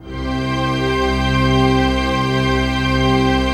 DM PAD2-11.wav